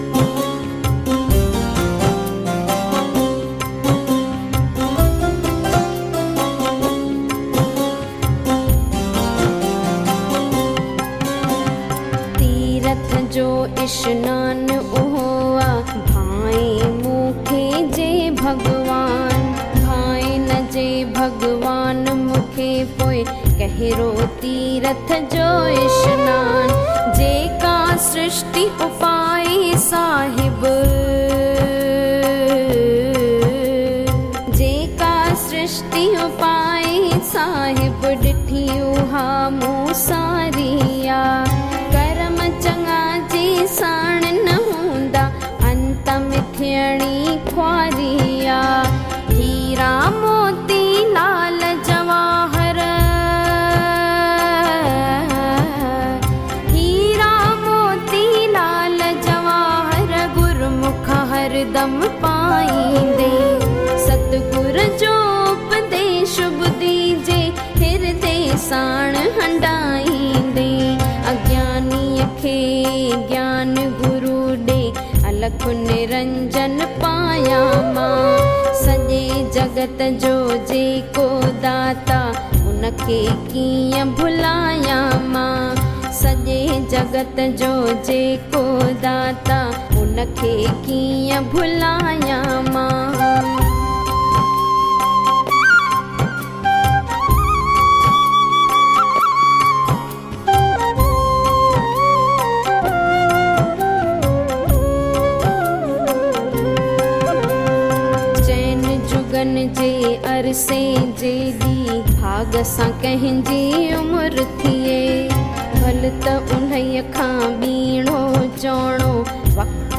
In Melodious Voice